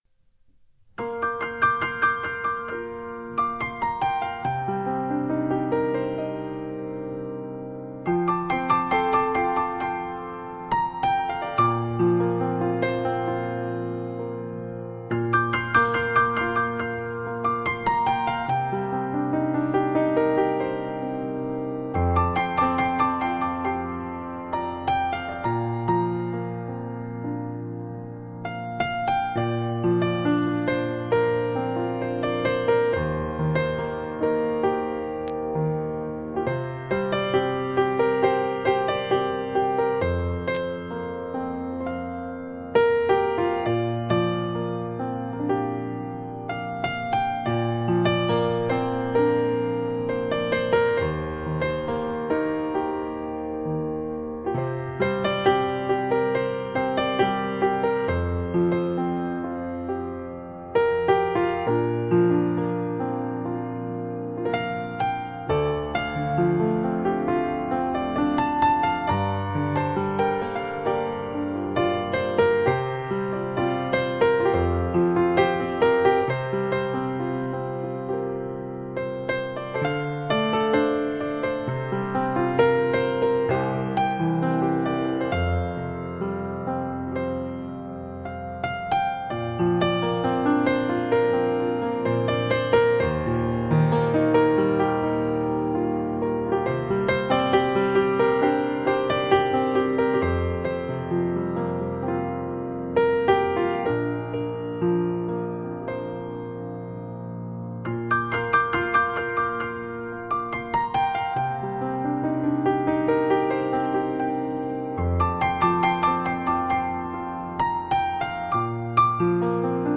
钢琴演奏
声音靓绝，自然传真，真正示范级发烧三角名琴录音，唯美钢琴大师以超凡醉人的手法，演绎华语世界经典名曲。